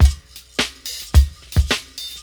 • 108 Bpm Breakbeat C# Key.wav
Free breakbeat sample - kick tuned to the C# note. Loudest frequency: 1552Hz
108-bpm-breakbeat-c-sharp-key-ryR.wav